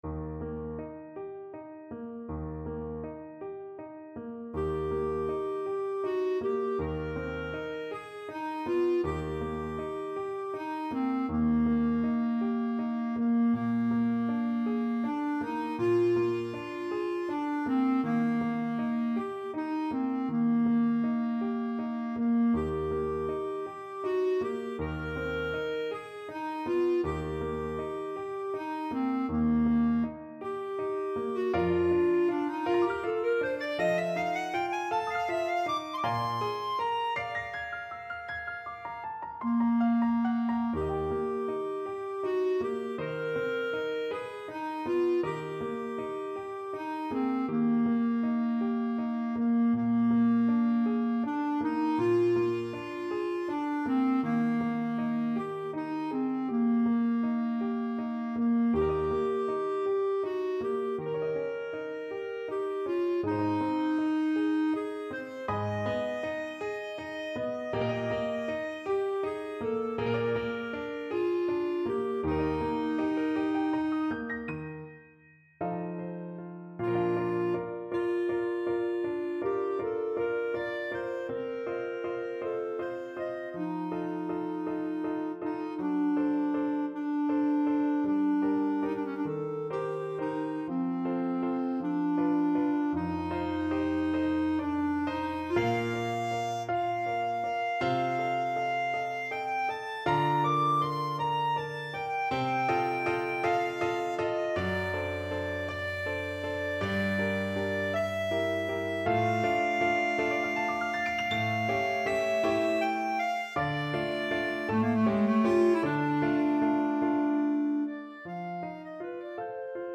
~ = 100 Andante espressivo
12/8 (View more 12/8 Music)
G4-Eb7
Classical (View more Classical Clarinet Music)